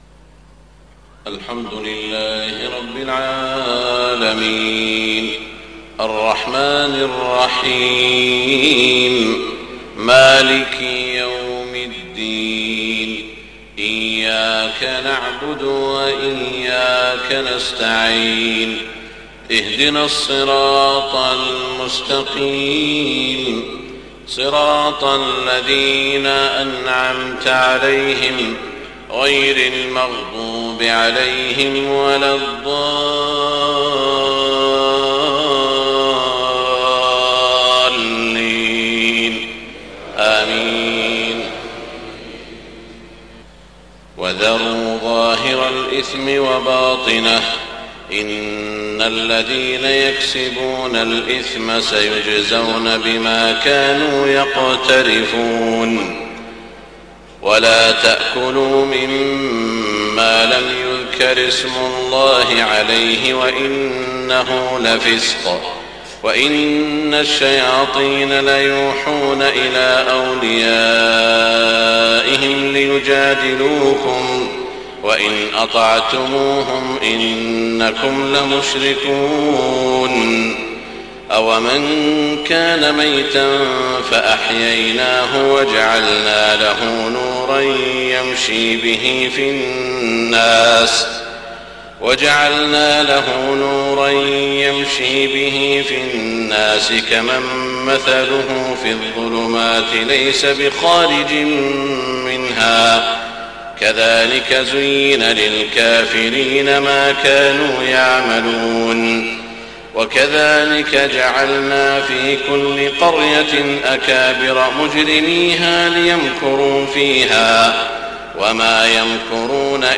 صلاة الفجر 2-6-1430هـ من سورة الأنعام > 1430 🕋 > الفروض - تلاوات الحرمين